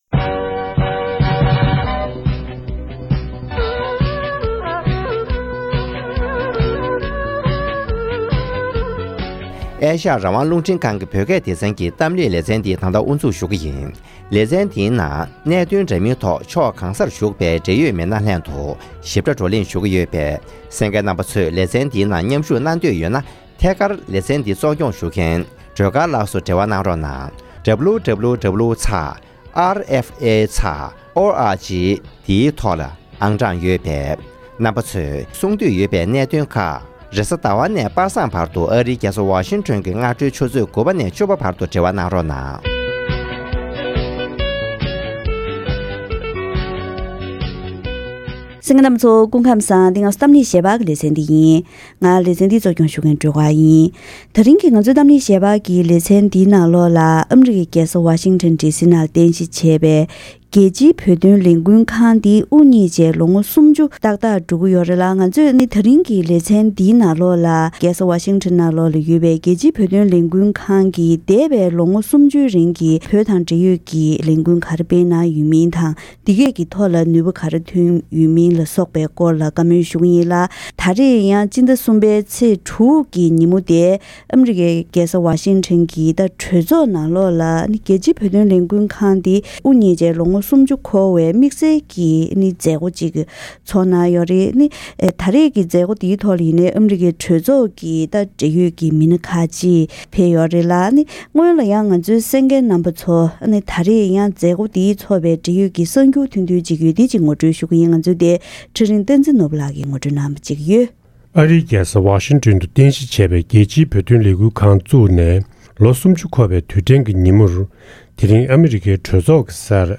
དེ་རིང་གི་གཏམ་གླེང་ལེ་ཚན་ནང་ཨ་རིའི་རྒྱལ་ས་ཝ་ཤིང་ཊོན་དུ་རྟེན་གཞི་བྱས་པའི་རྒྱལ་སྤྱིའི་བོད་དོན་ལས་འགུལ་ཁང་འདི་བཞིན་དབུ་བརྙེས་ནས་ལོ་ངོ་སུམ་བཅུ་འཁོར་བའི་སྐབས་དེར་ཐོག་མར་གནས་སྟངས་གང་འདྲའི་འོག་གསར་བཛུགས་གནང་བ།